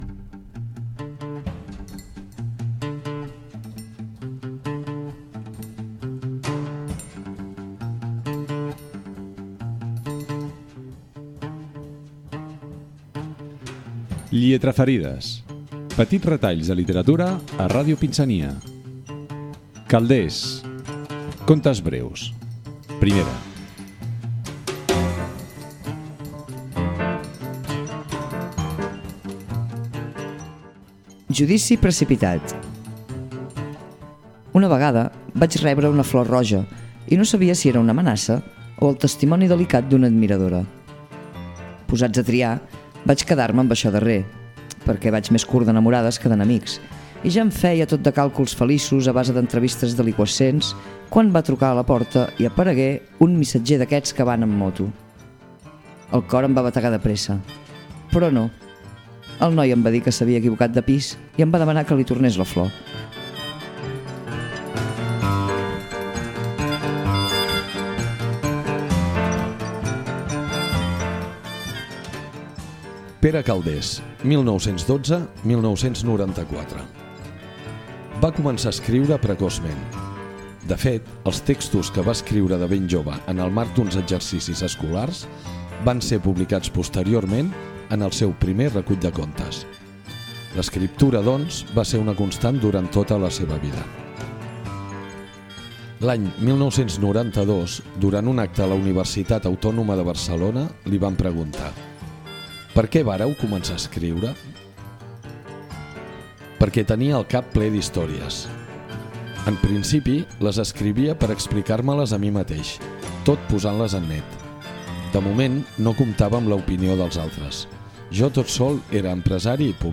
Calders: contes breus, lectura d'un conte, perfil biogràfic de l'escriptor, segon conte breu i comiat amb la identificació de l'emissora.
FM